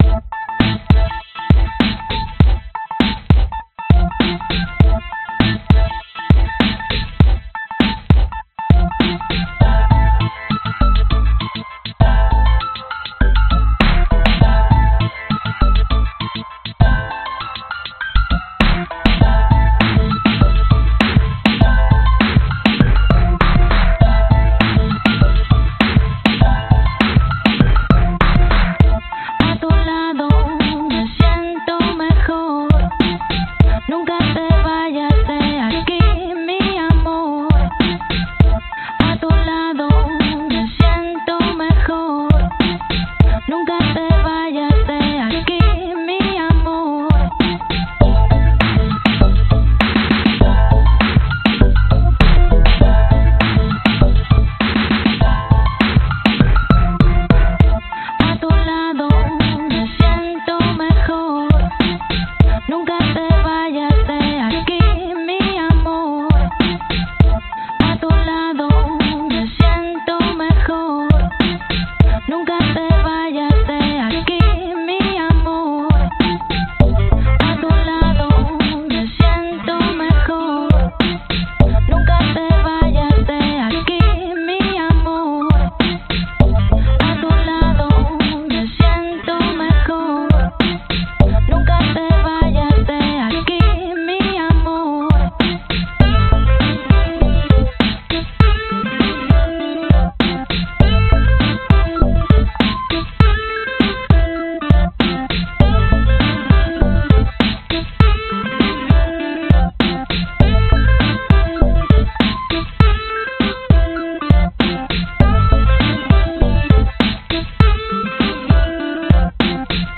标签： 环境 寒冷 电子 电子 女声 时髦 合成器 技术
声道立体声